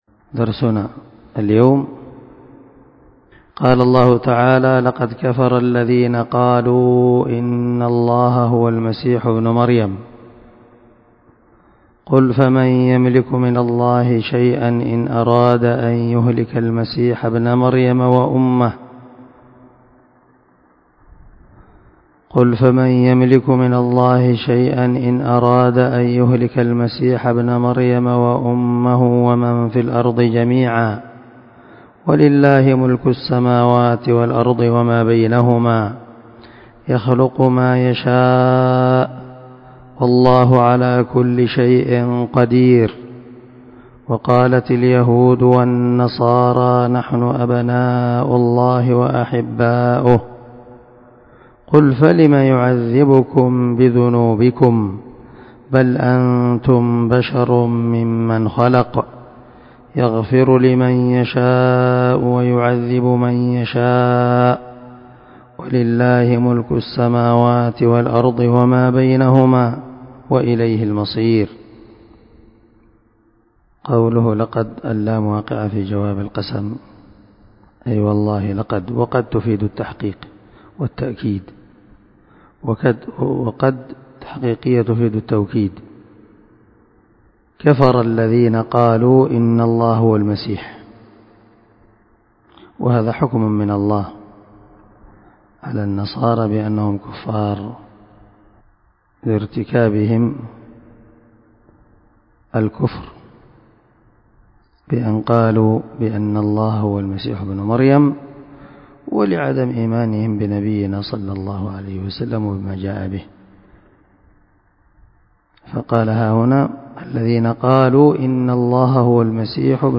350الدرس 17 تفسير آية ( 17 – 18 )من سورة المائدة من تفسير القران الكريم مع قراءة لتفسير السعدي
دار الحديث- المَحاوِلة- الصبيحة.